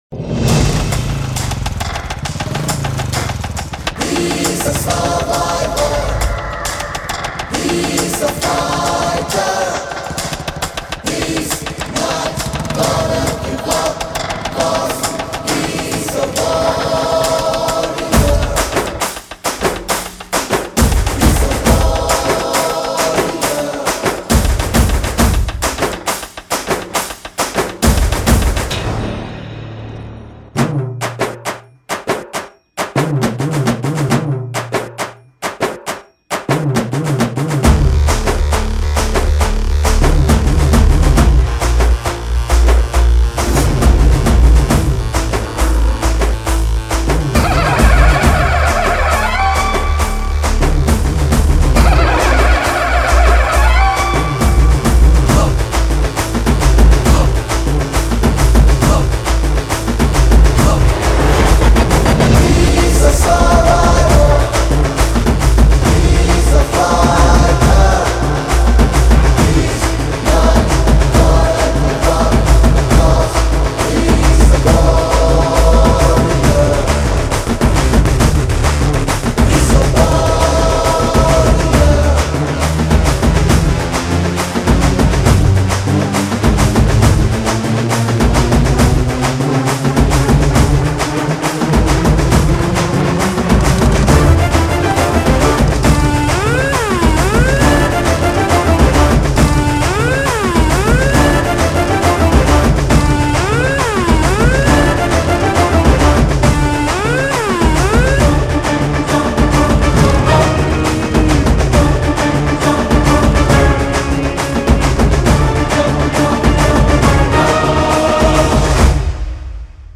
massive energetic mix